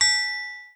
private_message.wav